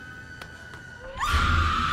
Sound Effects
Screaming Girls